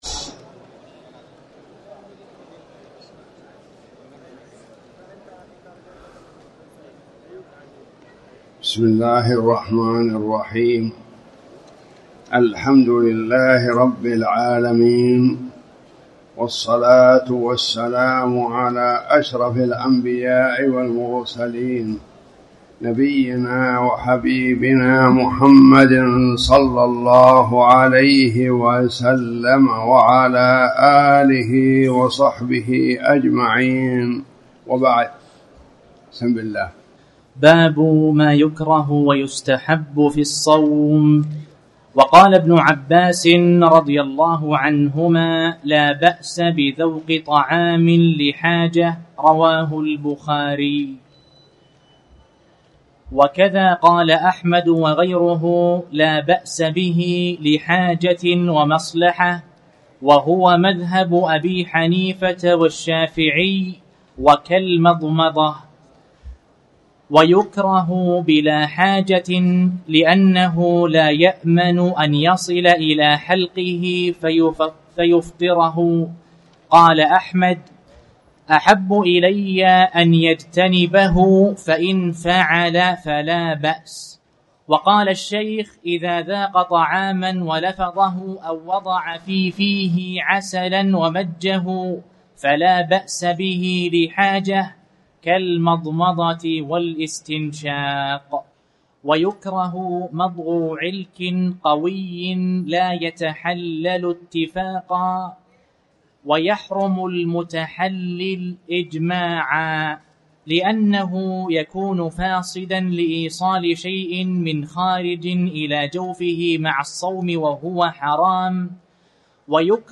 تاريخ النشر ٩ شوال ١٤٣٩ هـ المكان: المسجد الحرام الشيخ